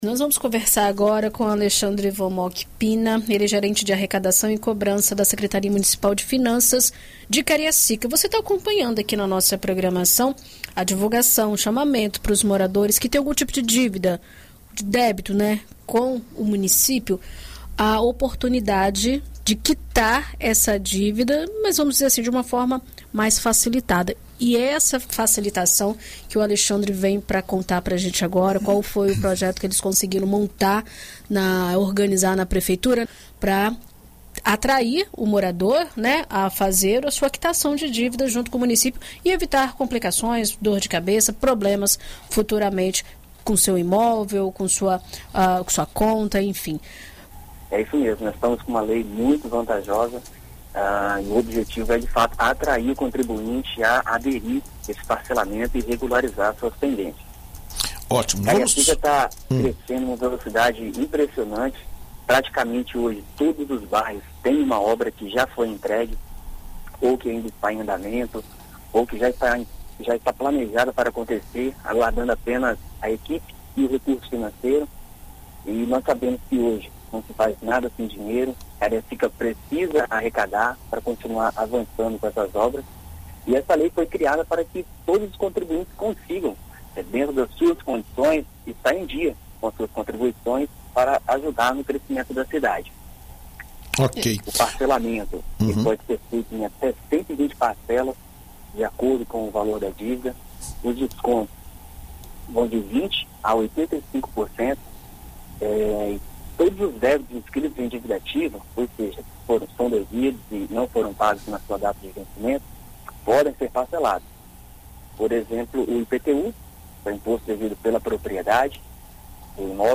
Em entrevista à BandNews FM Espírito Santo nesta segunda-feira (06)